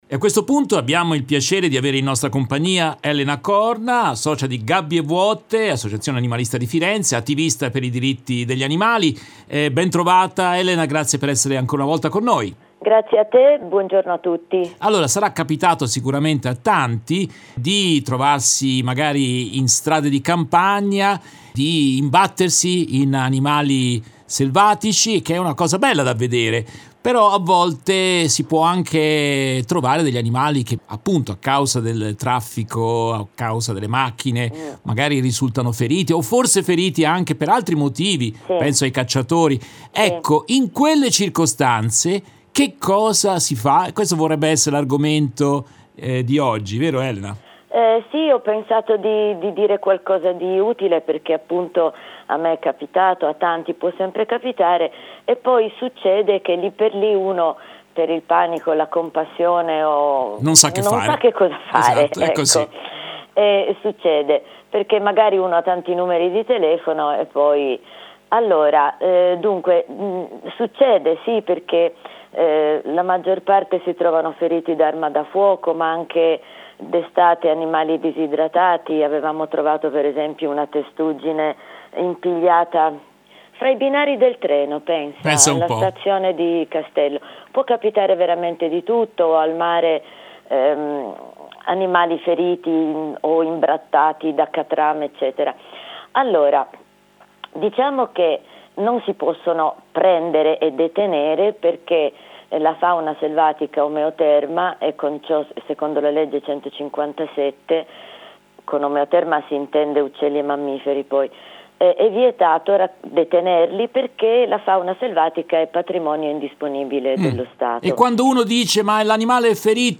In questa nuova puntata tratta dalla diretta del 25 novembre 2022